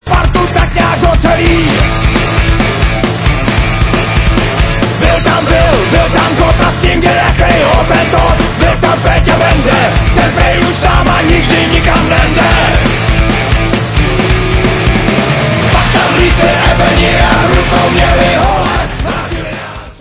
dvouměsíčním intenzívním natáčení ve studiu
sledovat novinky v kategorii Rock